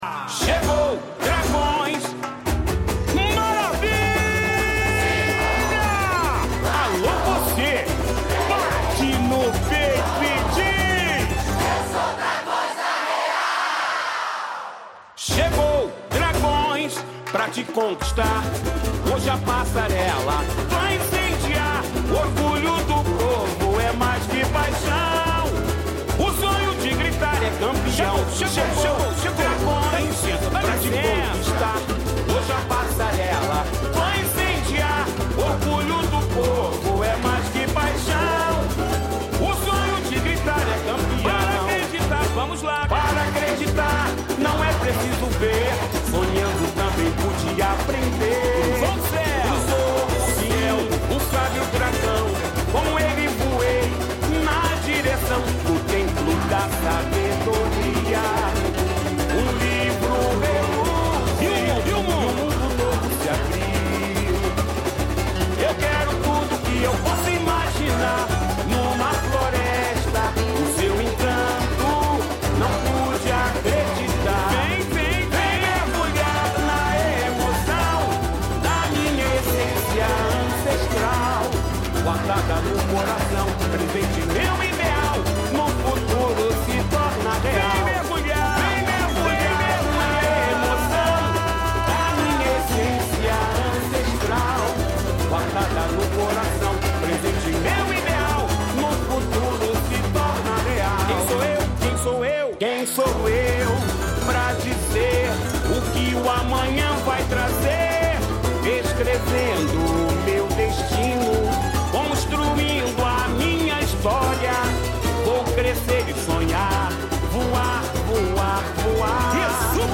Intérprete: